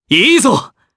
Clause-Vox_Happy4_jp.wav